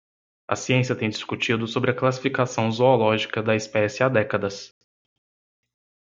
Pronounced as (IPA) /ˈso.bɾi/